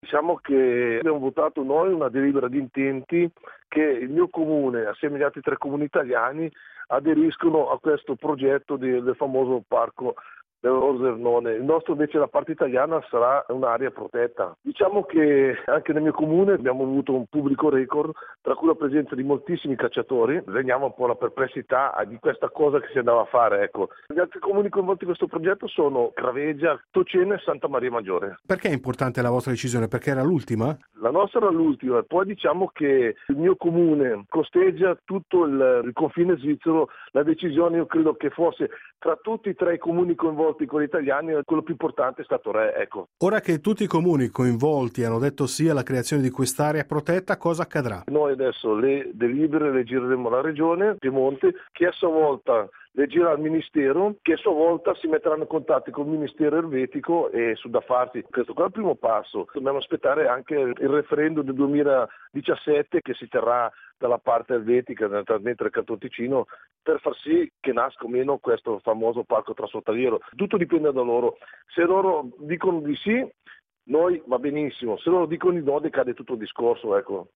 Oreste Pastore, sindaco di Re